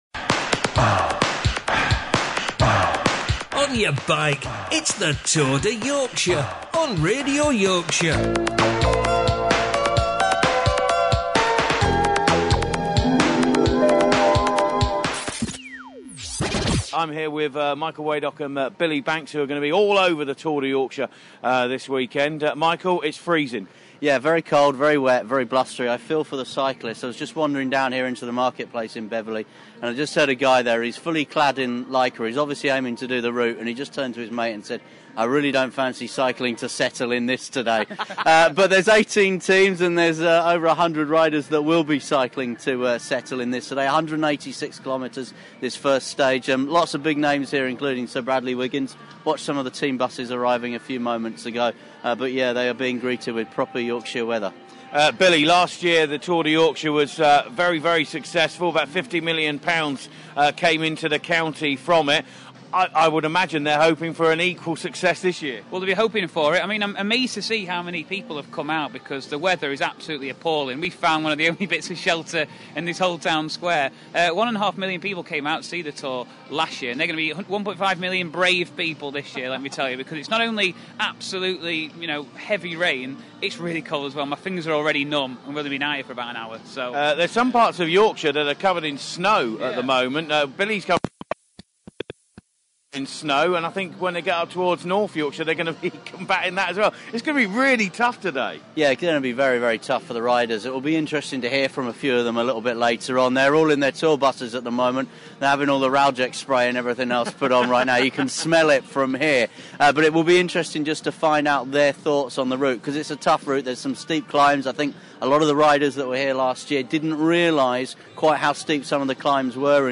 Highlights of Radio Yorkshire's coverage of the first day of the second annual Tour de Yorkshire which started in in Beverley and finished Settle.